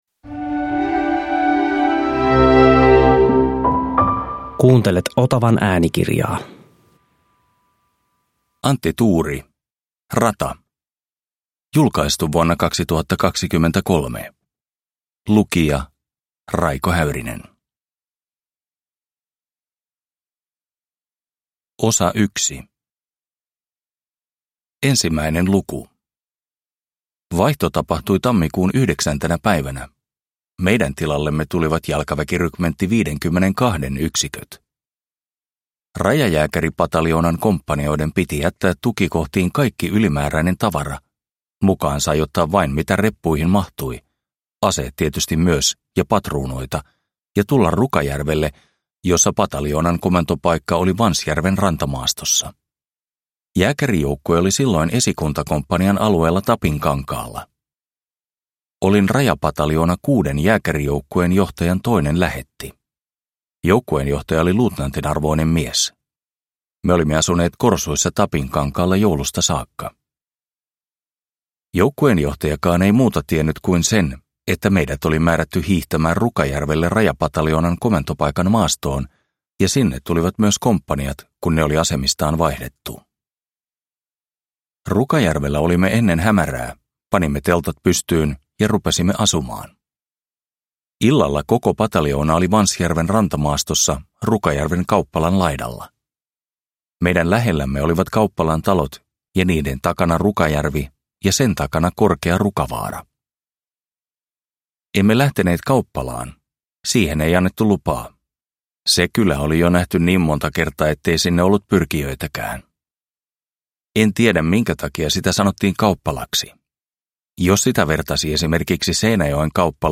Rata – Ljudbok – Laddas ner